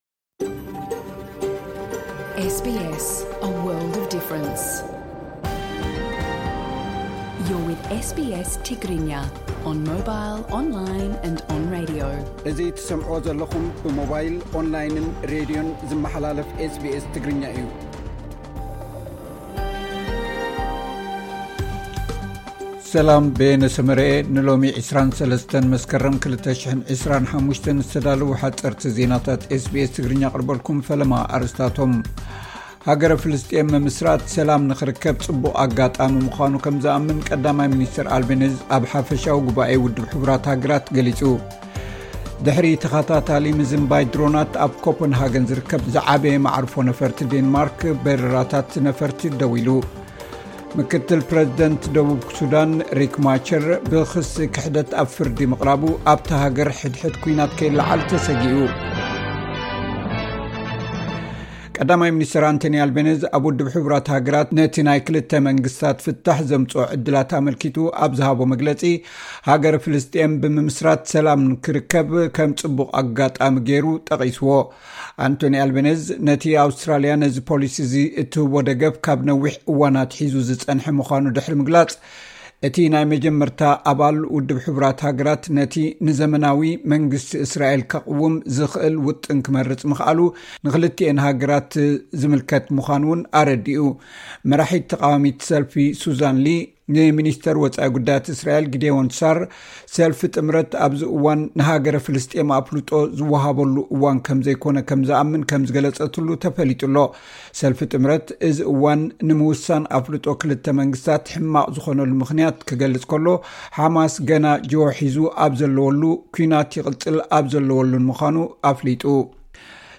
ሓጸርቲ ዜናታት ኤስ ቢ ኤስ ትግርኛ (23 መስከረም 2025)